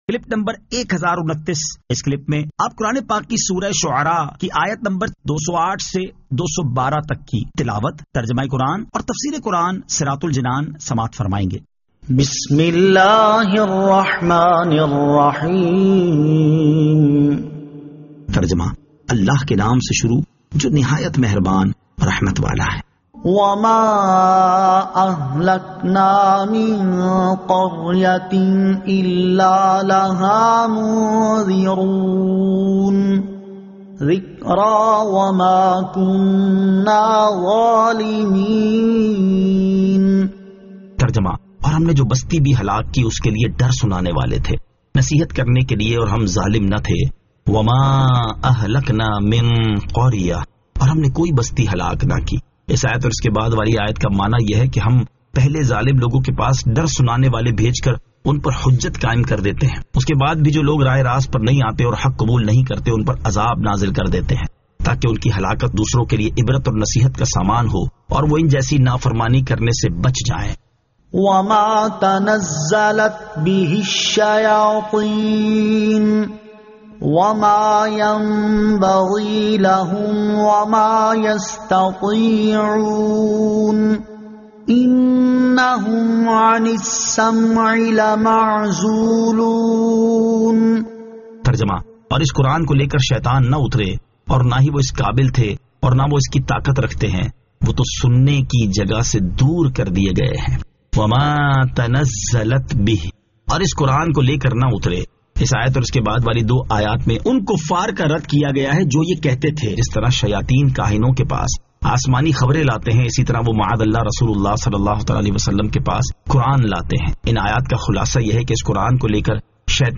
Surah Ash-Shu'ara 208 To 212 Tilawat , Tarjama , Tafseer